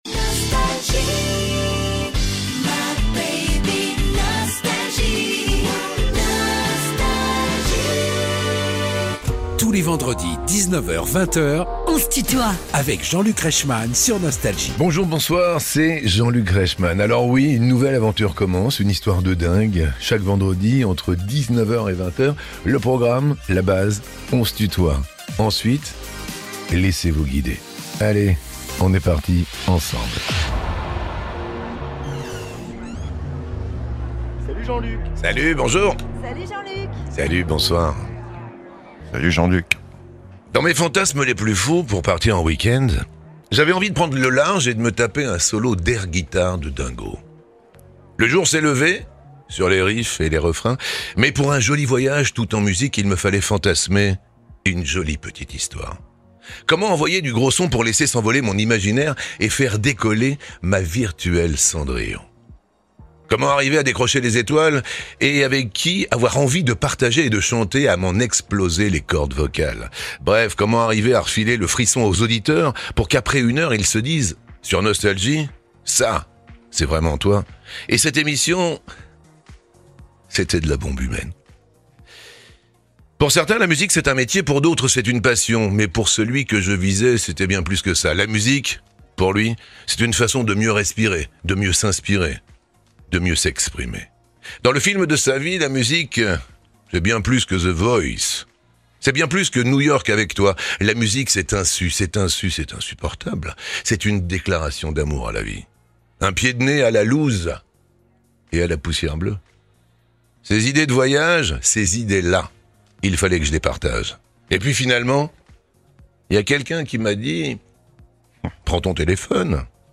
Invité de Jean-Luc Reichmann dans "On se tutoie ?..." : Louis Bertignac déroule le film de sa vie sur Nostalgie (Partie 1) ~ Les interviews Podcast
Louis Bertignac électrise les scènes depuis presque 50 ans avec la sensibilité et la générosité qui le caractérisent, Invité de Jean-Luc Reichmann dans "On se tutoie ?...", il nous déroule en musique le film de sa vie pour notre plus grand bonheur !